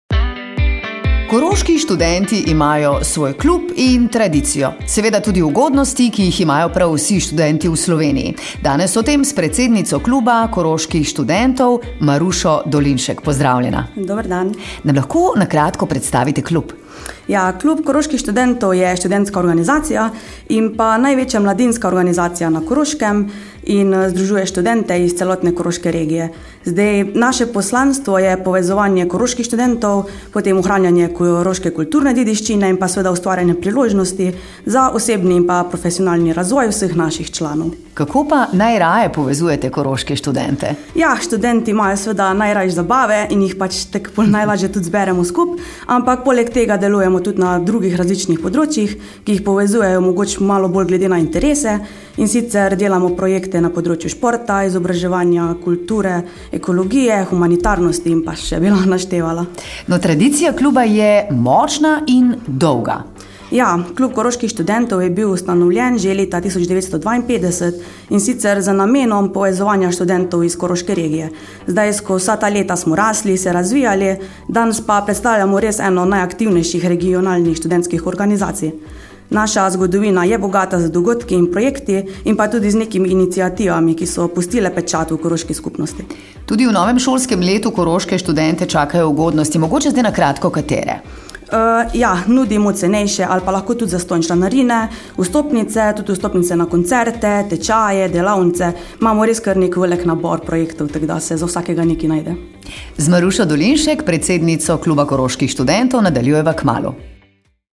pogovor KKS 1.mp3